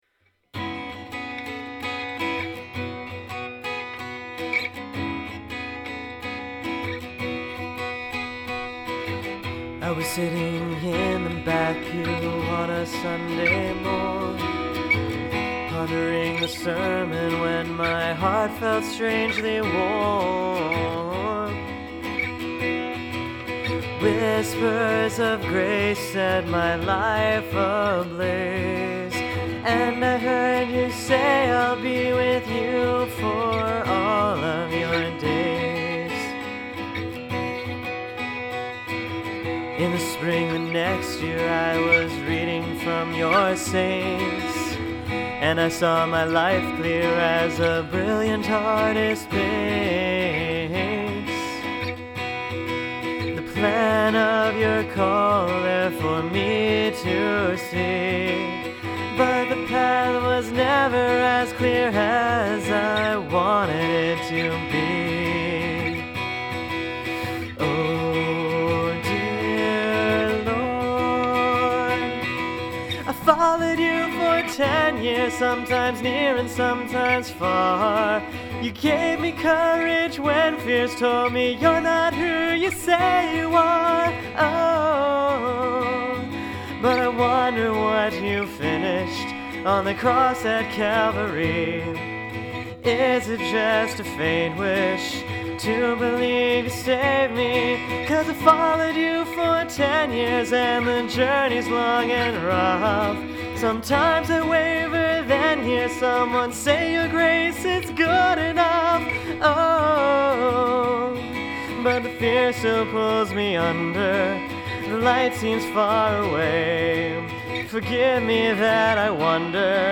For each song, I gave myself no more than two hours to write and one hour to record it. These are by no means polished songs; they are the responses of my heart to Christ crucified.